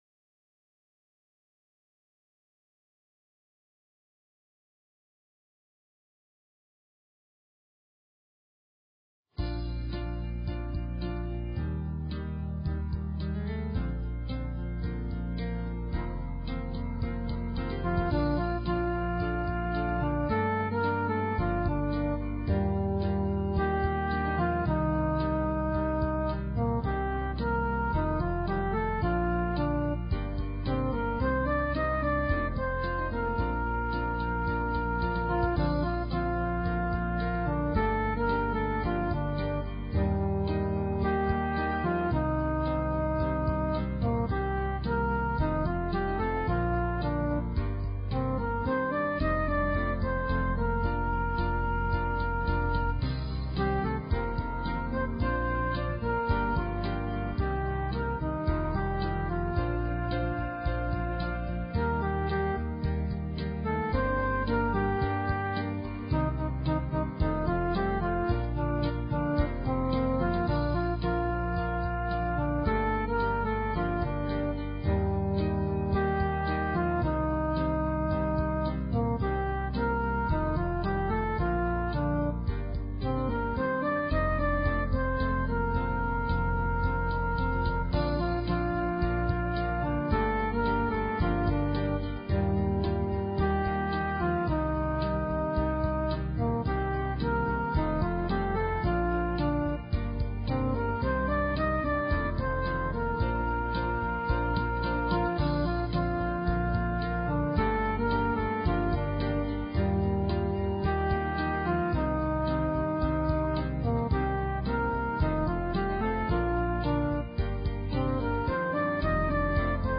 Talk Show Episode, Audio Podcast, Peaceful_Planet and Courtesy of BBS Radio on , show guests , about , categorized as